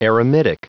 Prononciation du mot eremitic en anglais (fichier audio)
Prononciation du mot : eremitic